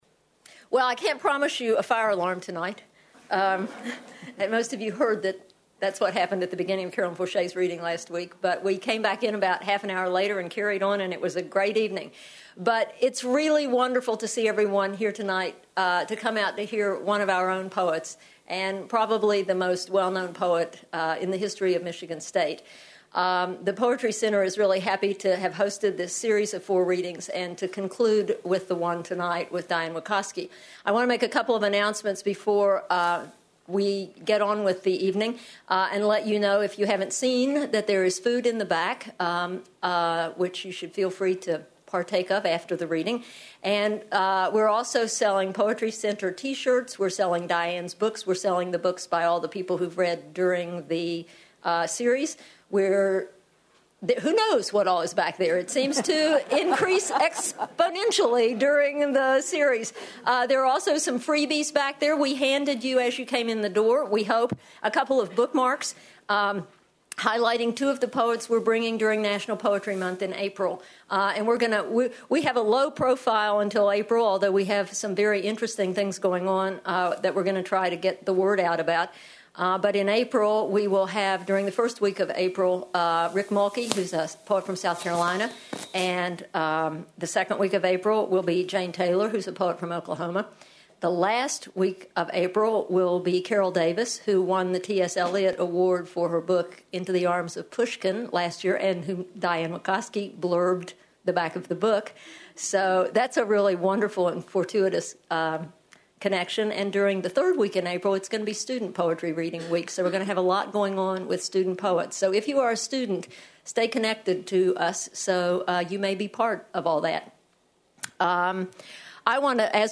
Michigan State University Distinguished Professor Diane Wakoski reads at the fourth annual Poetry Center Reading
Wakoski discusses the concept of creating a personal mythology and the difference between being a truth teller and revealing secrets. She also reads poems from a new manuscript entitled "The diamond dog".